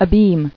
[a·beam]